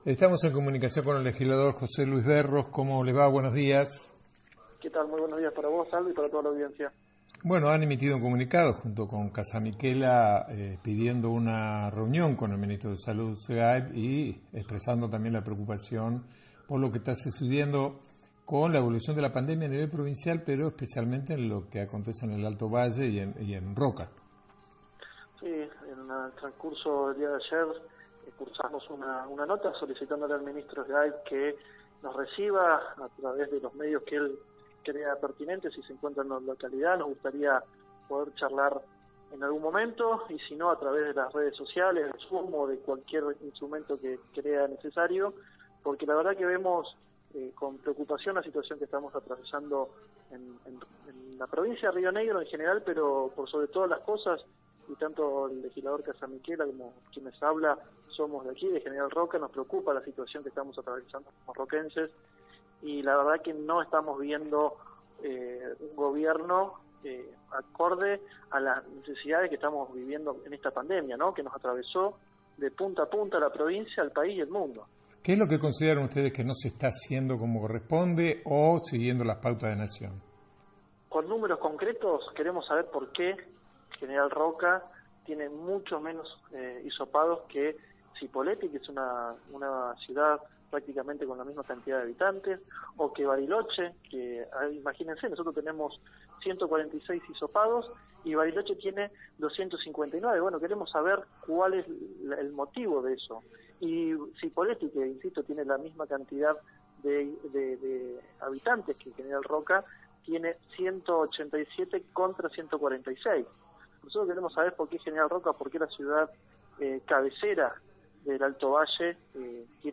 En comunicación telefónica con “Entre Bardas” el legislador José Luis Berros manifestó que se le ha enviado una carta al Ministro para pautar una reunión por los medios que él crea conveniente.